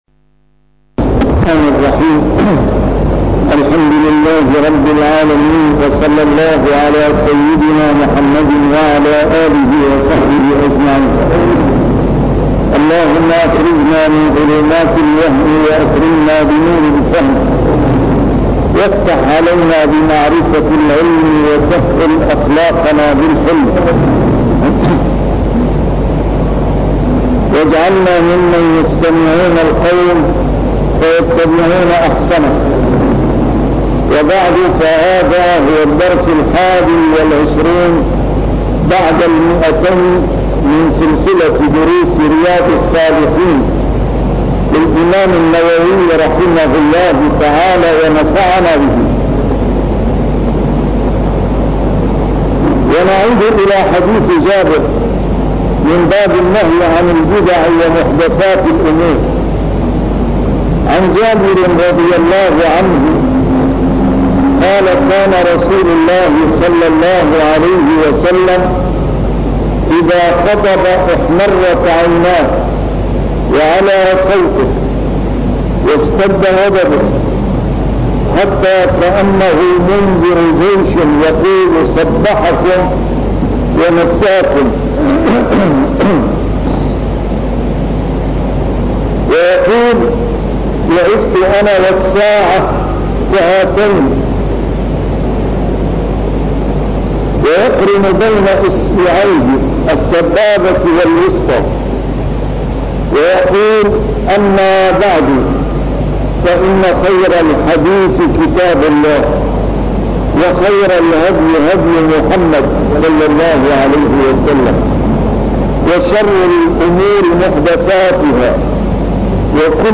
A MARTYR SCHOLAR: IMAM MUHAMMAD SAEED RAMADAN AL-BOUTI - الدروس العلمية - شرح كتاب رياض الصالحين - 221- شرح رياض الصالحين: النهي عن البدع